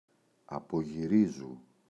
απογυρίζω [apoγi’rizo]
απογυρίζου.mp3